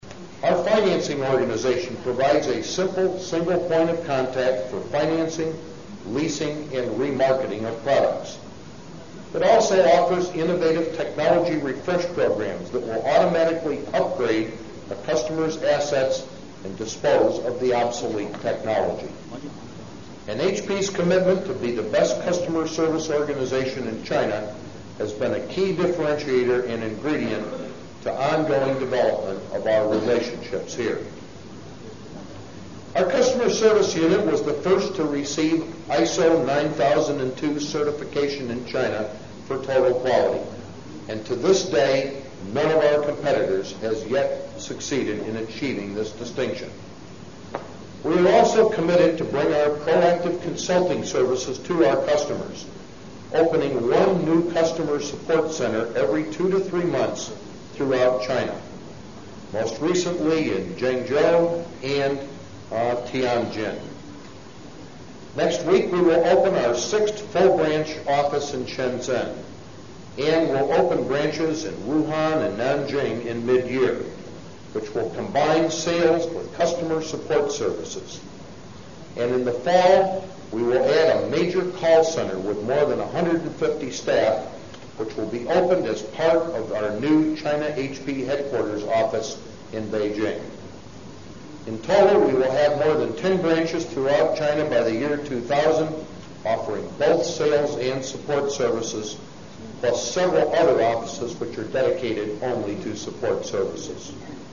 这些财富精英大多是世界著名公司的CEO，在经济领域成就斐然。在演讲中他们或讲述其奋斗历程，分享其成功的经验，教人执着于梦想和追求；或阐释他们对于公司及行业前景的独到见解，给人以启迪和思考。